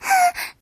moan7.ogg